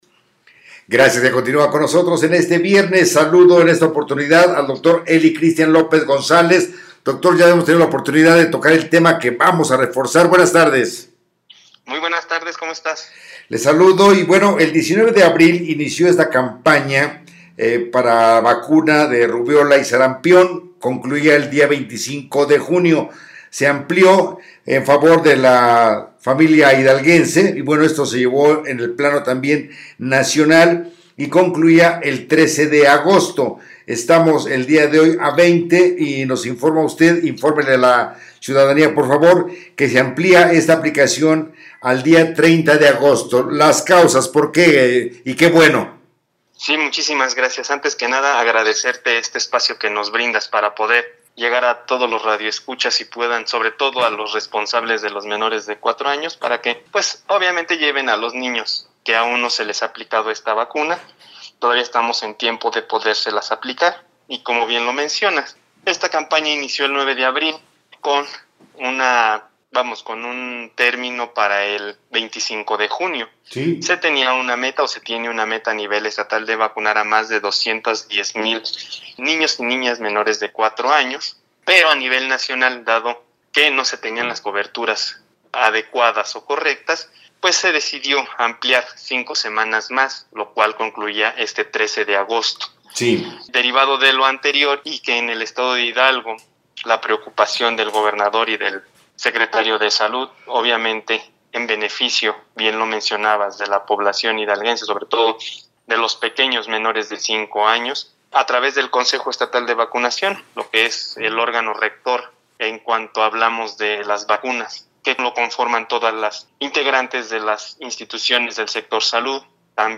Entrevista / En beneficio de las y los menores hidalguenses, se amplía periodo de vacunación contra sarampión y rubeola